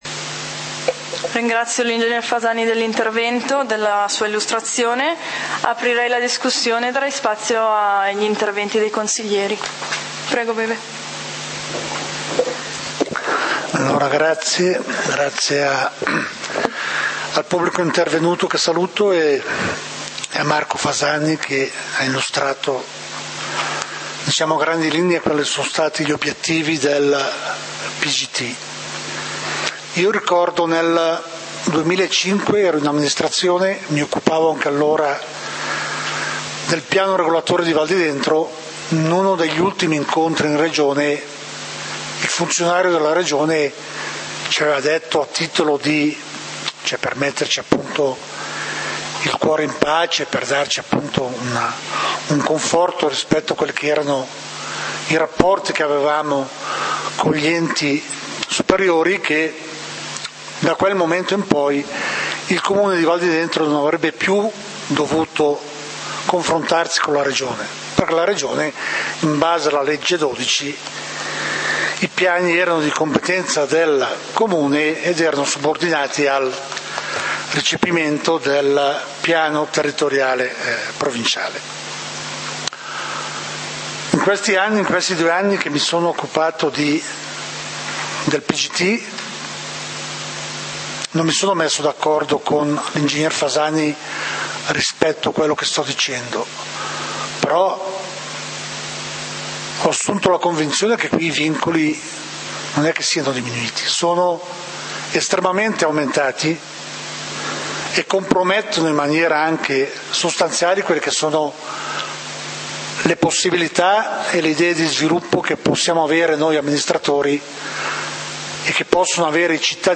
Consiglio comunale di Valdidentro del 30 Giugno 2014
Consiglio comunale del 30 Giugno 2014 torna alla lista dei punti Punto 3a: Piano di governo del territorio adottato con deliberazione di consiglio comunale n. 49 del 30.12.2013. Esame e decisione in merito alle osservazioni e ai pareri pervenuti. Approvazione definitiva; Intervento dell'assessore Gabriele Viviani.